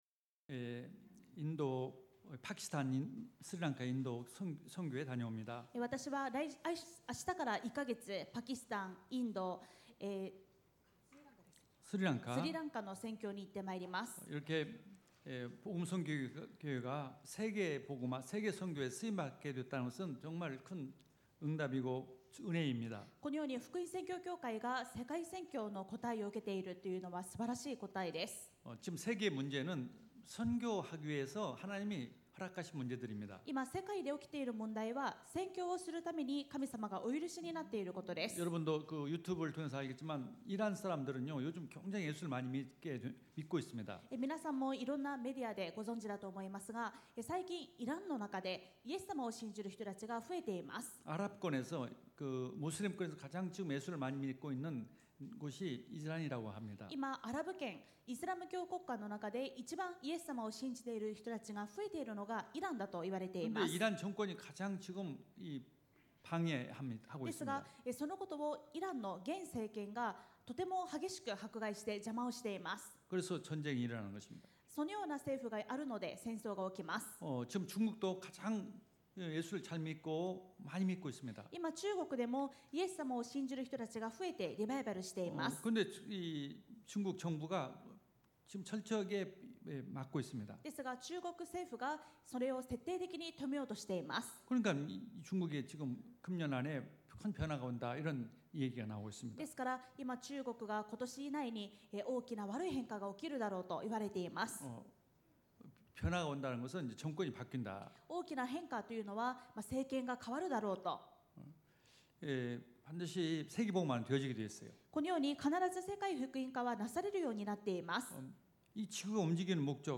主日3部メッセージ